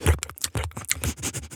dog_lick_smell_04.wav